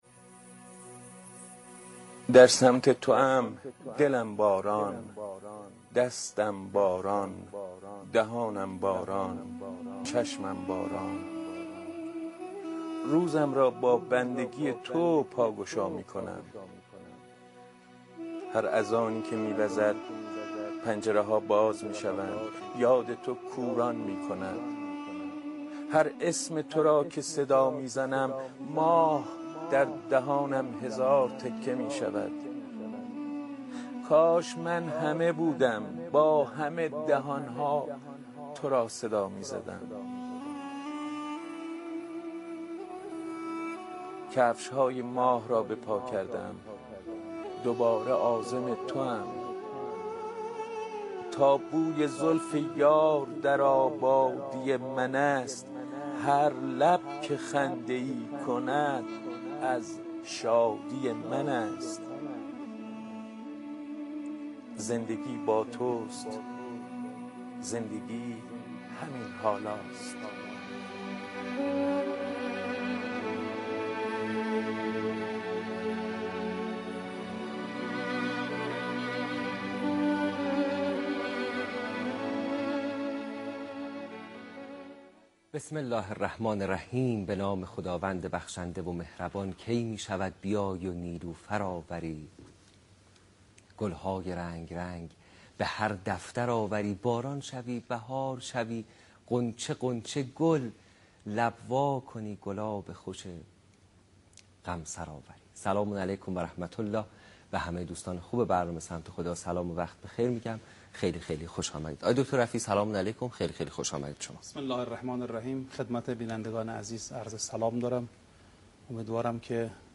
دانلود تفسیر قرآن (باید ها و نباید ها)